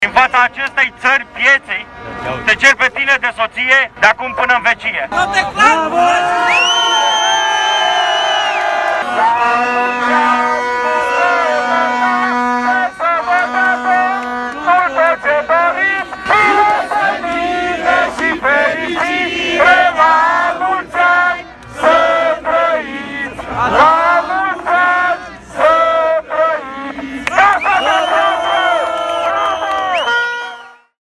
În mijlocul protestatarilor adunați ieri la Guvern, unul dintre manifestanți și-a cerut iubita în căsătorie.
Pentru a fi sigur că se face auzit, a folosit portavocea pe care o utiliza ca să scandeze împotriva guvernului.
13febr-13-cerere-in-casatorie-la-protest.mp3